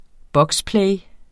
Udtale [ ˈbʌgsˌplεj ]